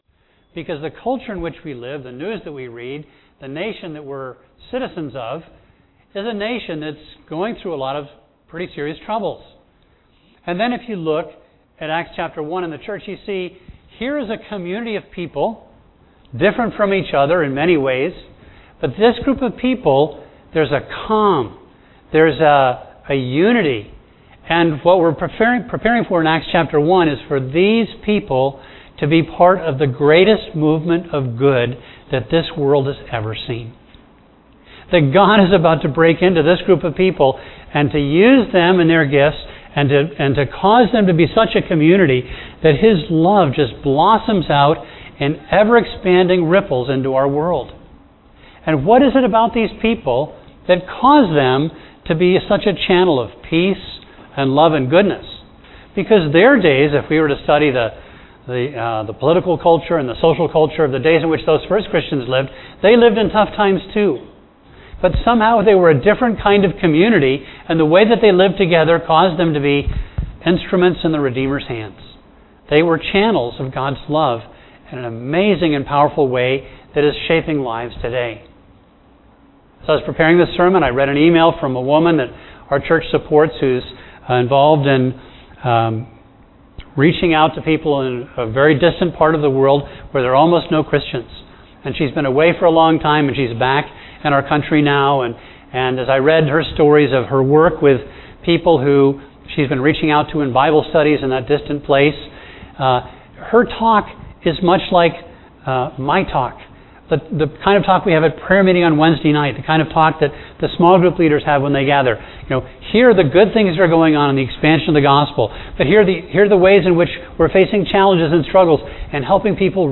A message from the series "The Acts."